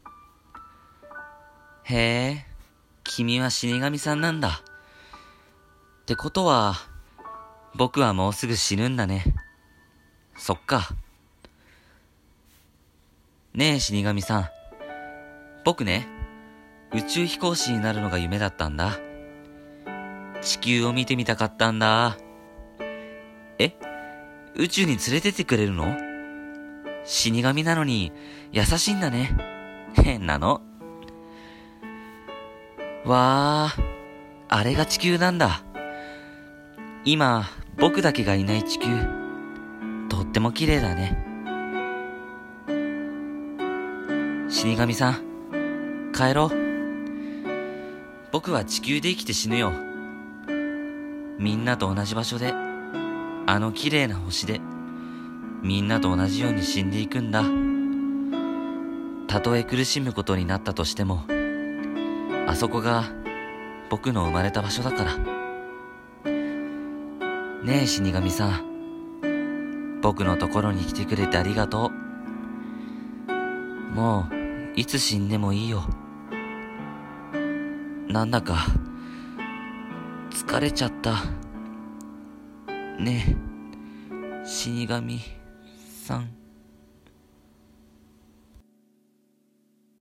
声劇「死神と少年」